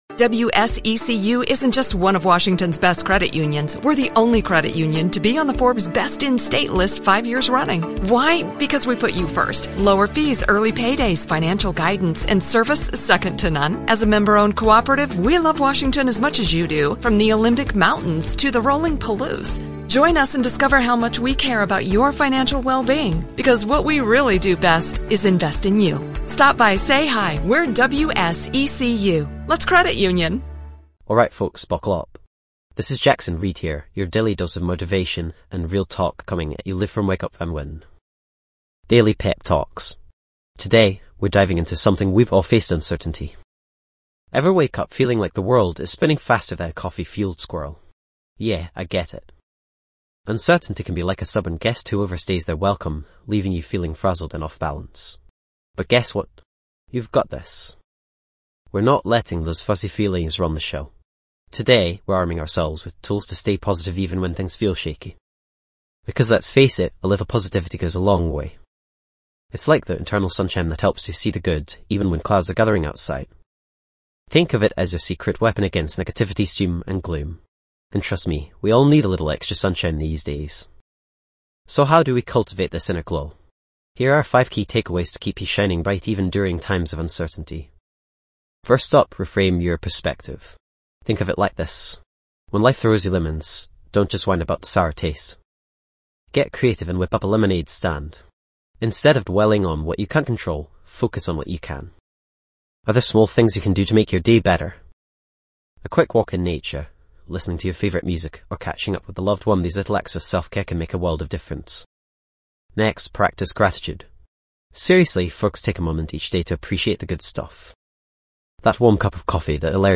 Podcast Category:. Personal Development, Motivational Talks
This podcast is created with the help of advanced AI to deliver thoughtful affirmations and positive messages just for you.